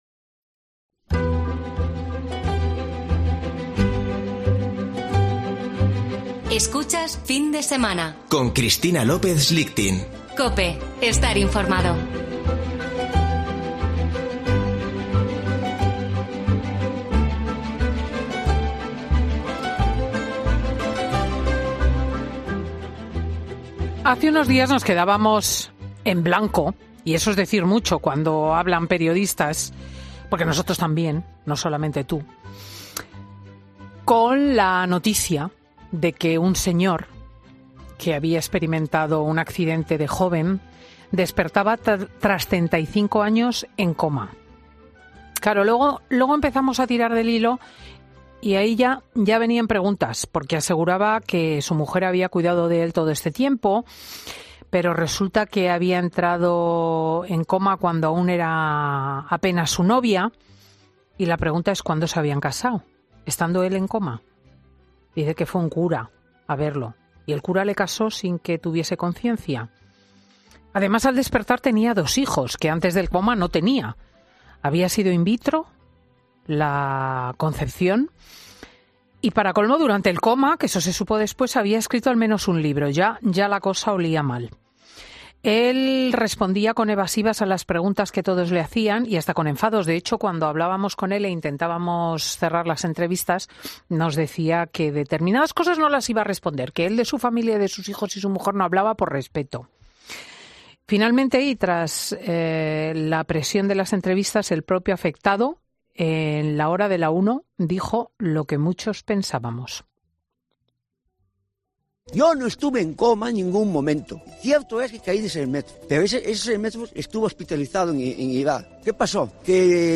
Redacción digital Madrid - Publicado el 13 nov 2021, 12:20 - Actualizado 18 mar 2023, 04:21 3 min lectura Descargar Facebook Twitter Whatsapp Telegram Enviar por email Copiar enlace Escucha ahora 'Fin de Semana' . "Fin de Semana" es un programa presentado por Cristina López Schlichting , prestigiosa comunicadora de radio y articulista en prensa, es un magazine que se emite en COPE , los sábados y domingos, de 10.00 a 14.00 horas.